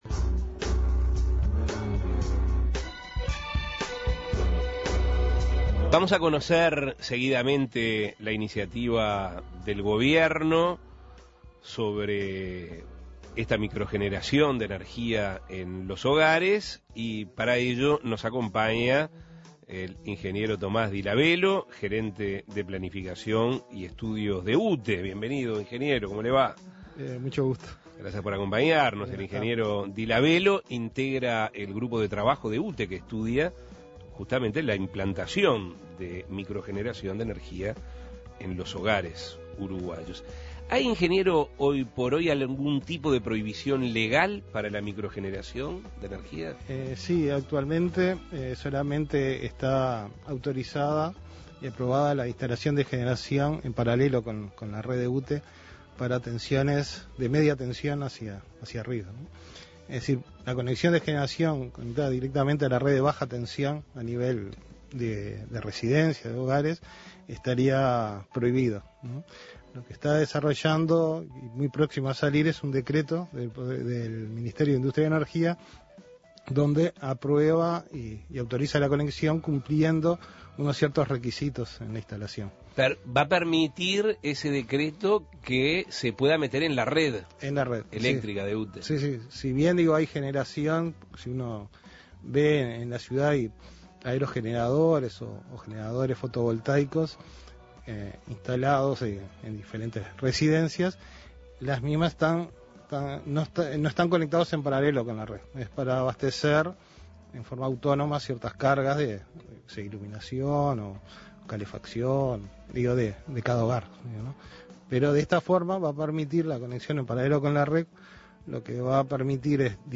La iniciativa está enmarcada dentro de la política de eficiencia energética que el gobierno viene impulsando en los últimos años. Escuche la entrevista.